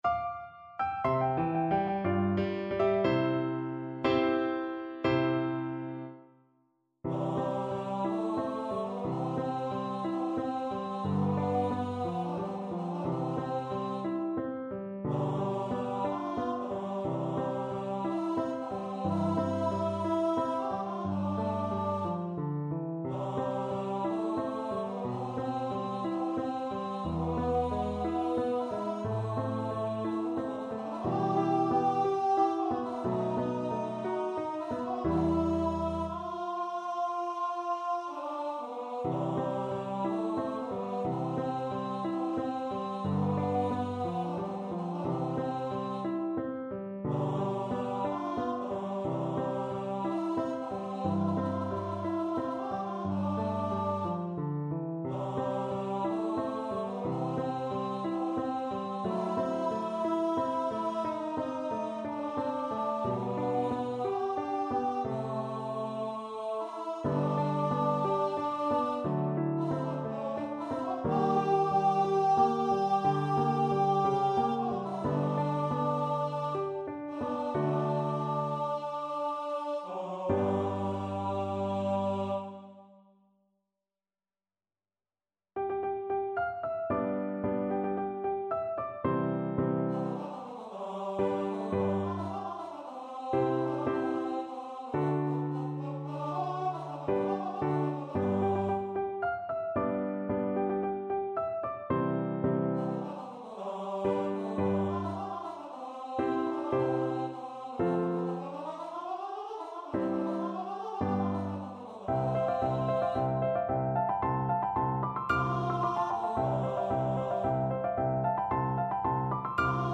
Tenor Voice
C major (Sounding Pitch) (View more C major Music for Tenor Voice )
2/4 (View more 2/4 Music)
Andante =c.60
Classical (View more Classical Tenor Voice Music)